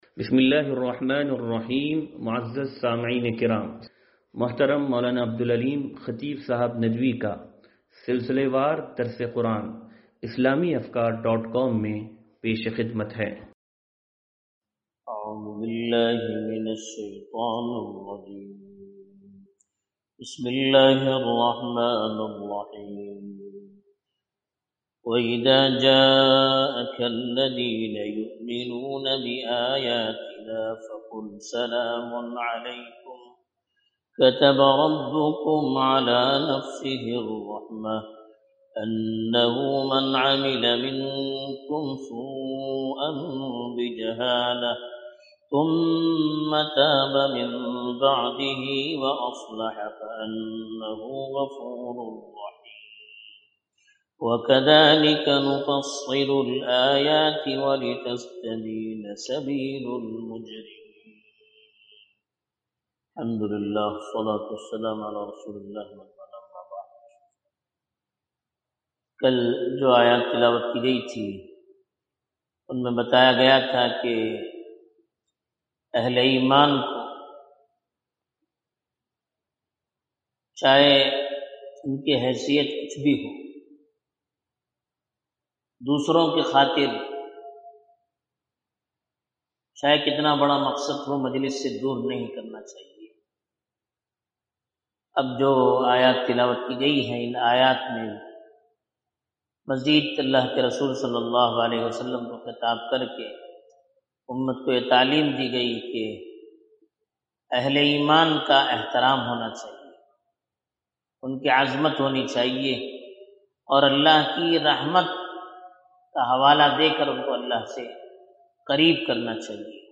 درس قرآن نمبر 0528